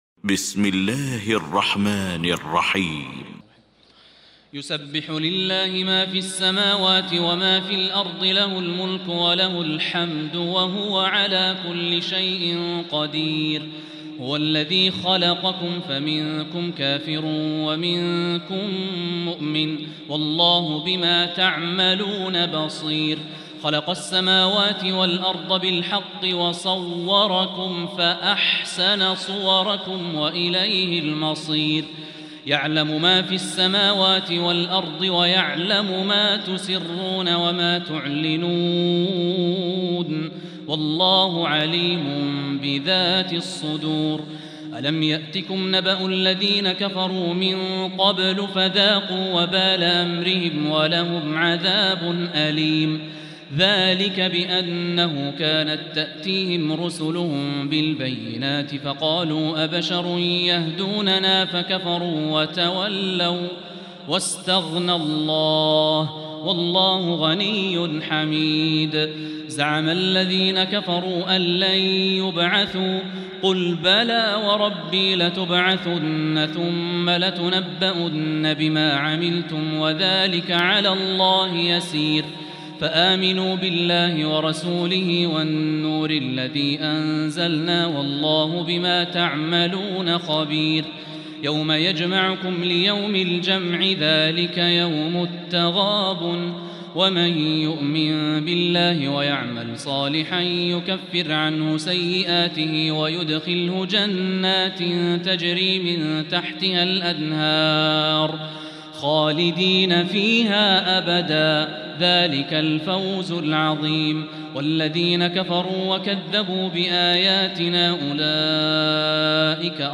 سورة التغابن | تراويح الحرم المكي عام 1445هـ